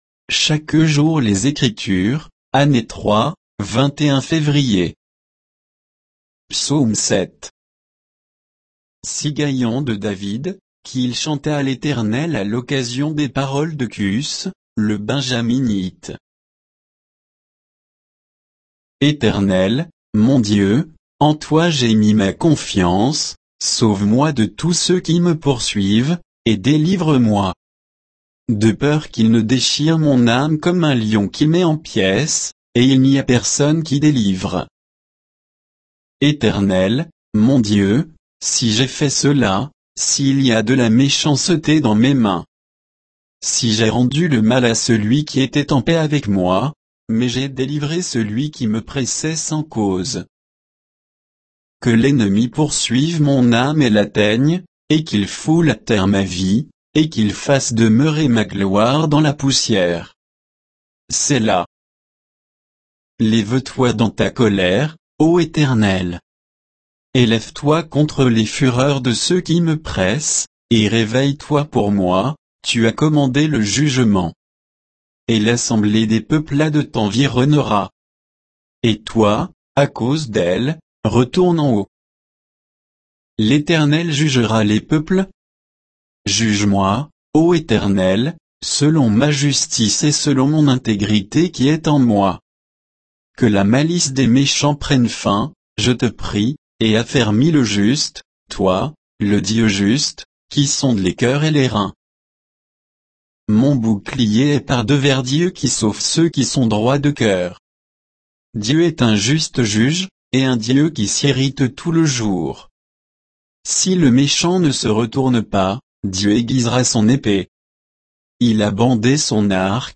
Méditation quoditienne de Chaque jour les Écritures sur Psaume 7